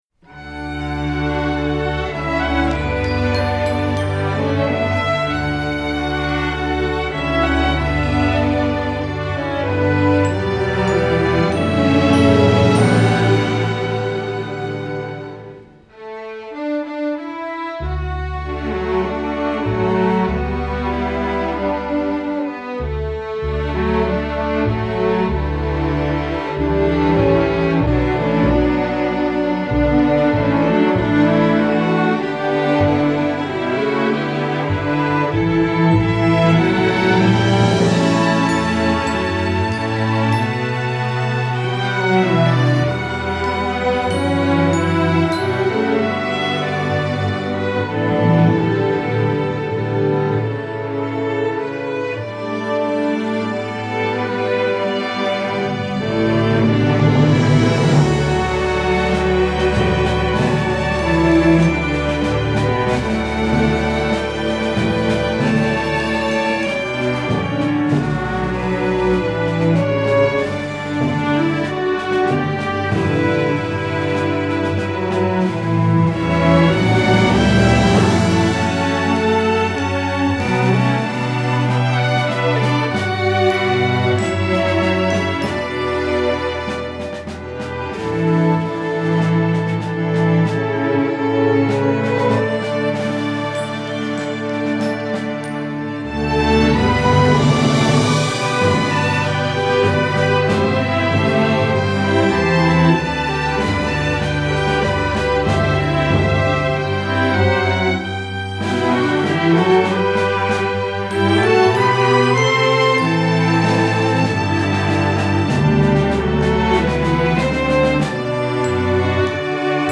Instrumentation: string orchestra
folk